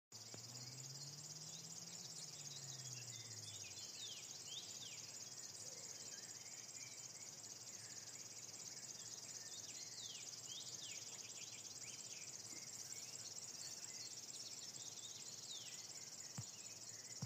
Birds -> Warblers ->
River Warbler, Locustella fluviatilis
StatusSinging male in breeding season